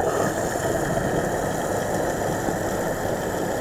SubAtomicFuelPump.wav